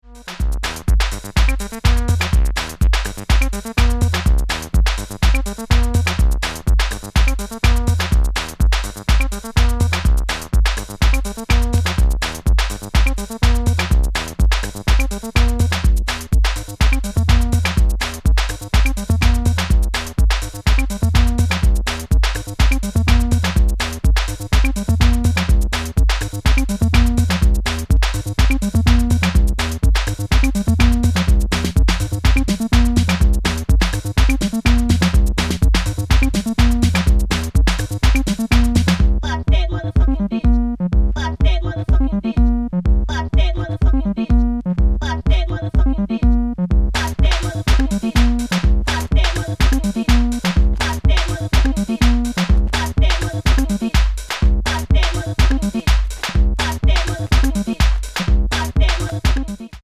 Ghetto tech!!!! And one very cool acid track.
House